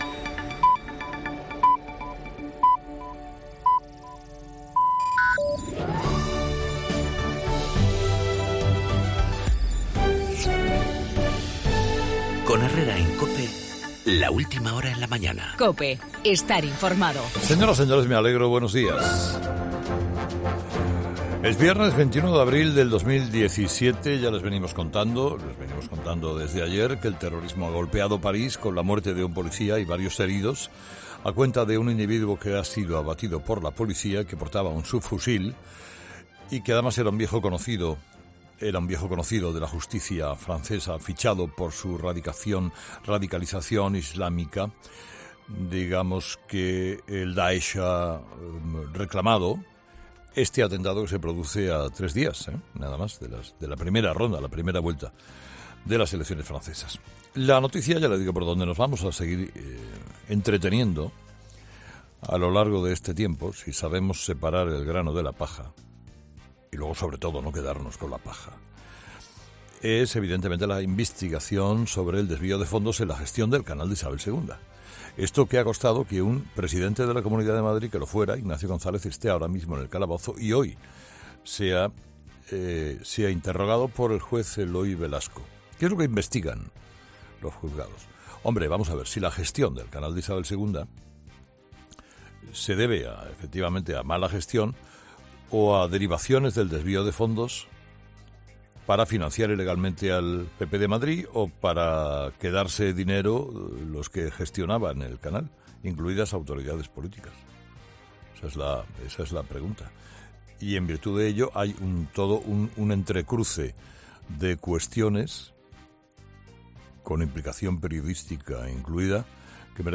El avance de la operación Lezo que investiga el desvío de fondos en el Canal de Isabel II y los 25 años de la inauguración del AVE, en el monólogo de Carlos Herrera a las 8 de la mañana.